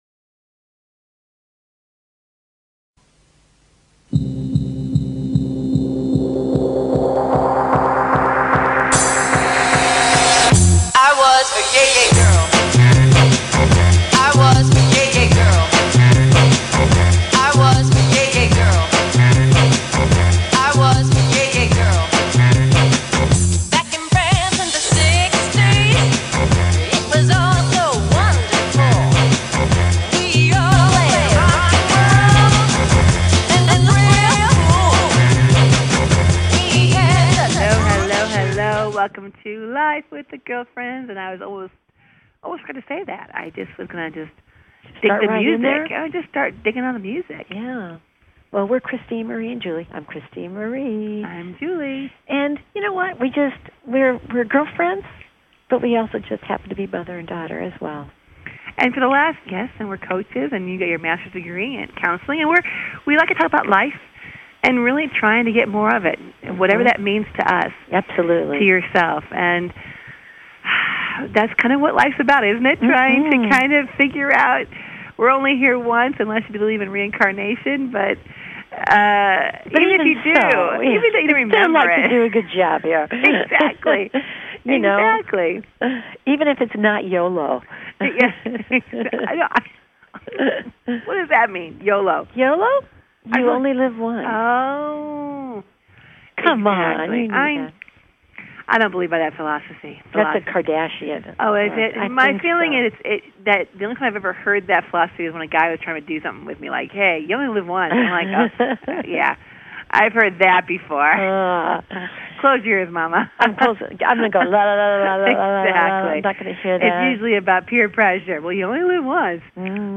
They welcome a wide range of guest to their den for some juicy conversation.
And join the girlfriends up close and personal for some daily chat that’s humorous, wholesome, and heartfelt.